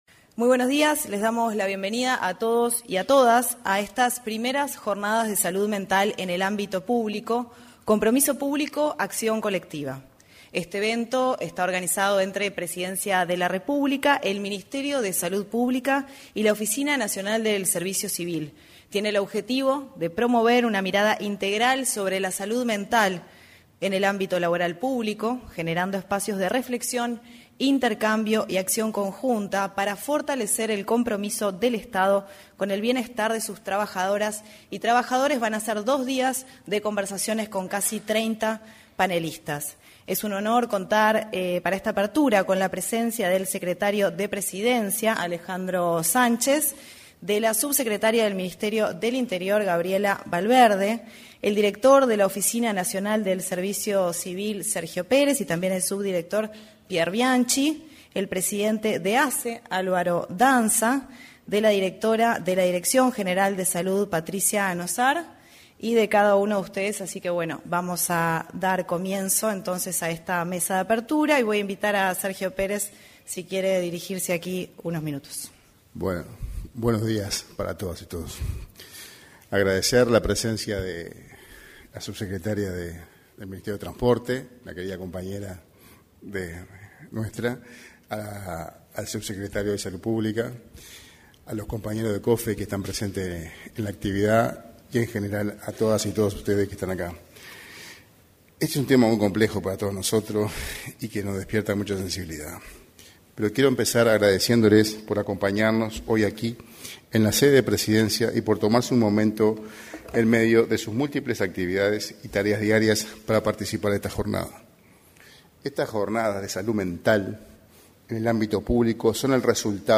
Se realizaron, en el auditorio del anexo a la Torre Ejecutiva, las Primeras Jornadas de Salud Mental en el Ámbito Público.
En la oportunidad, se expresaron el director de la Oficina Nacional del Servicio Civil, Sergio Pérez, y el secretario de la Presidencia de la República, Alejandro Sánchez.